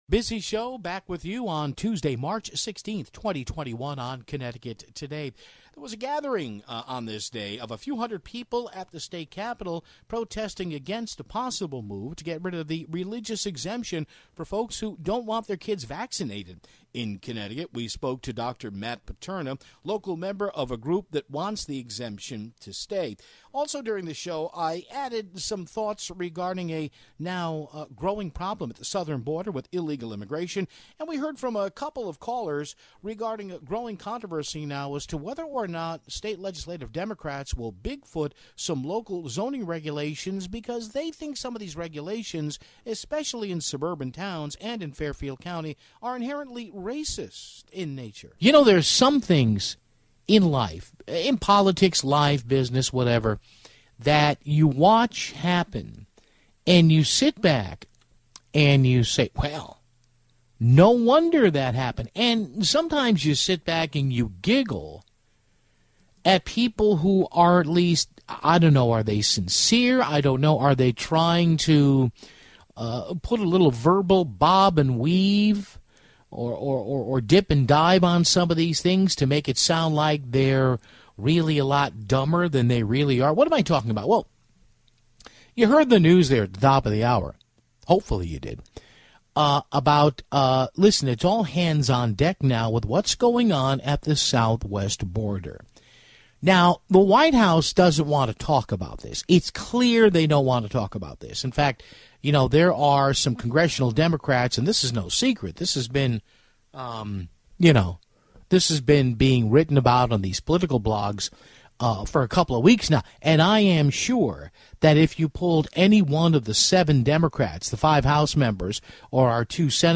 took a couple calls on the topics of zoning